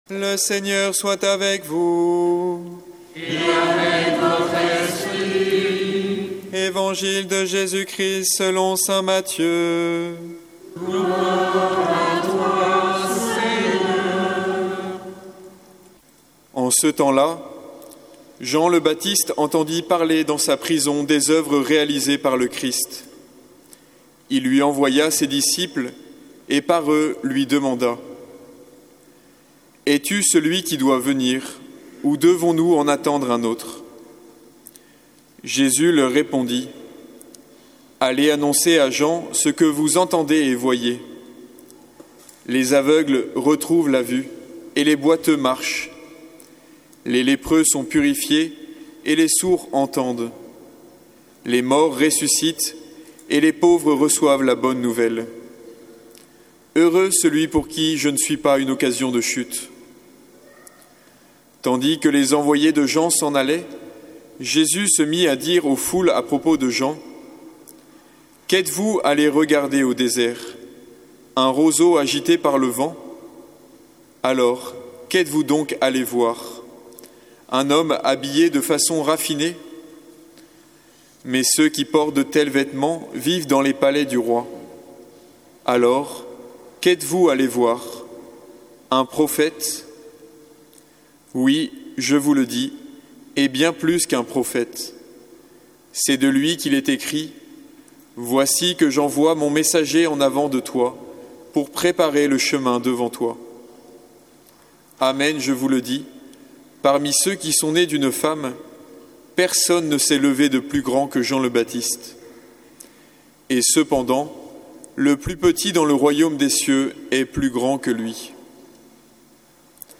Évangile de Jésus Christ selon saint Matthieu avec l'homélie